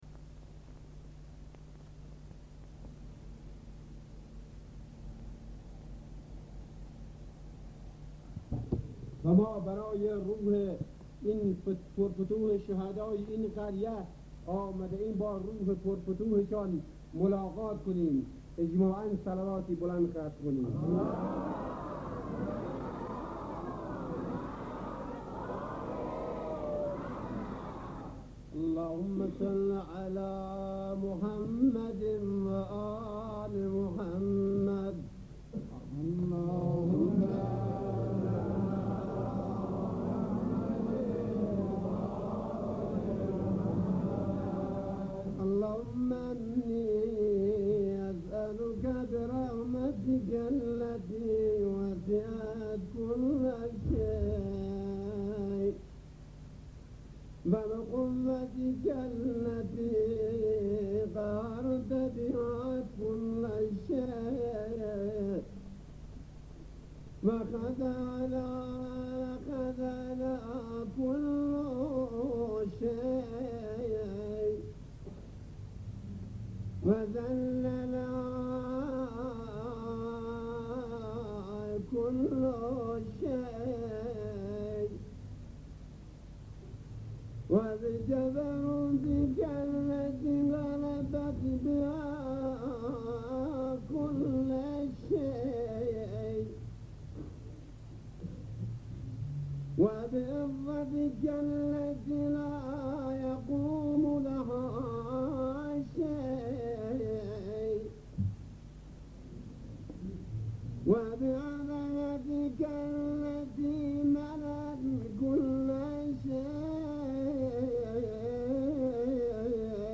دعای کمیل